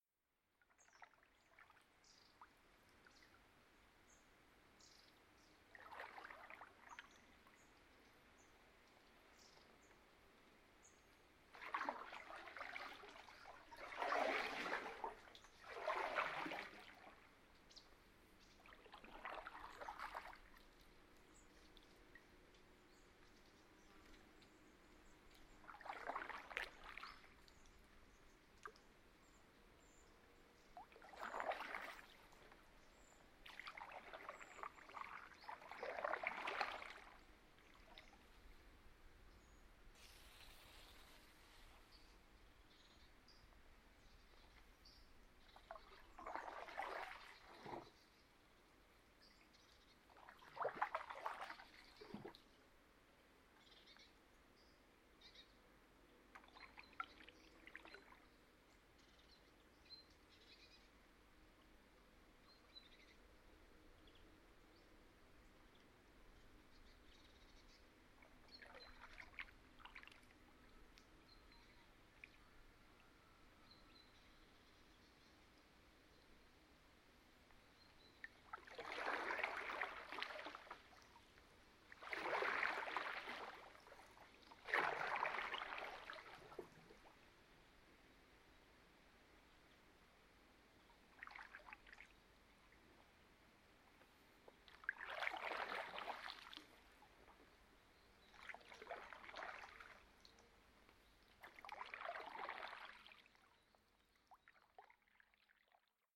17 kanufahrt im naturschutzgebiet taubergiessen
Field Recording Series by Gruenrekorder
17_kanufahrt_im_naturschutzgebiet_taubergiessen.mp3